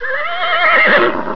caballo.wav